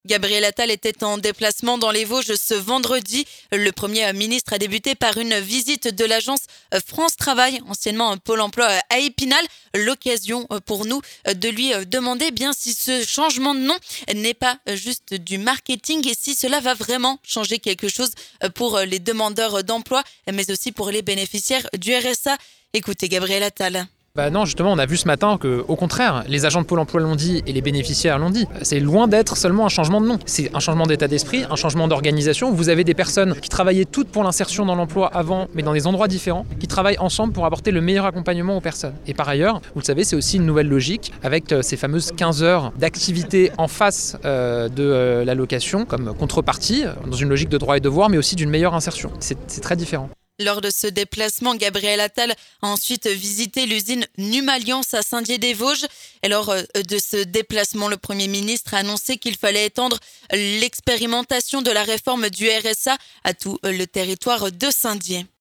Gabriel Attal s'exprime en exclusivité sur Vosges FM concernant le changement de nom de Pôle Emploi en France Travail et sur ce que cela va changer.